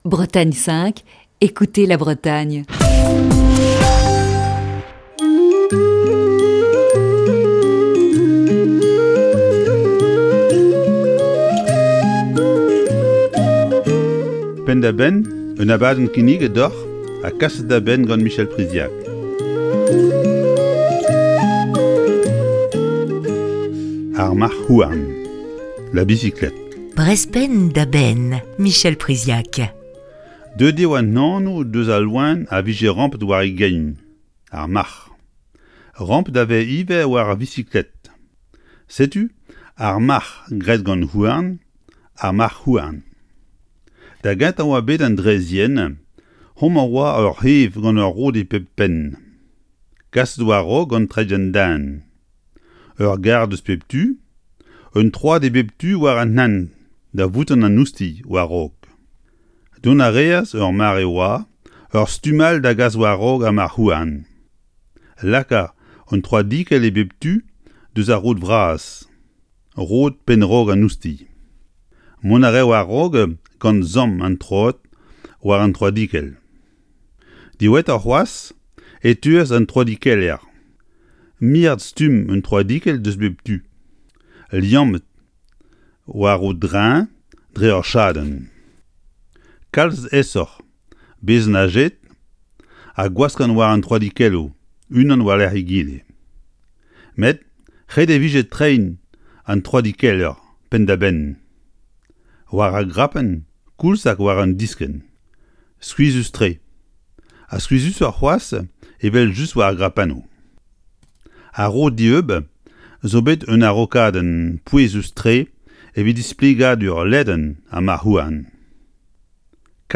Chronique du 8 février 2021.